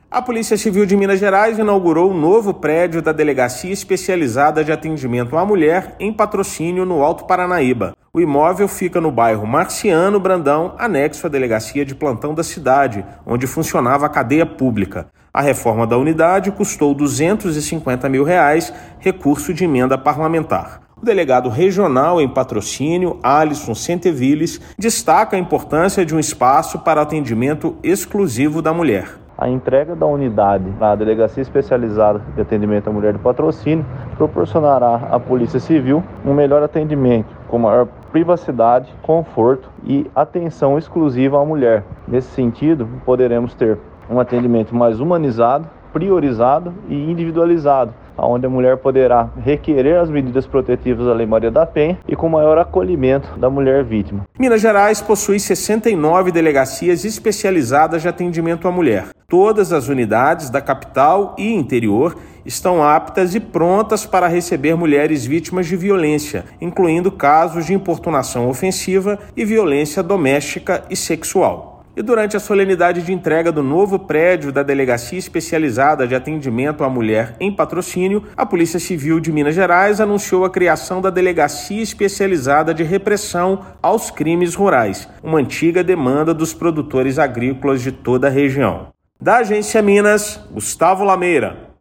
Durante a solenidade, ainda foi anunciada a criação da Delegacia Especializada de Repressão a Crimes Rurais na cidade. Ouça matéria de rádio.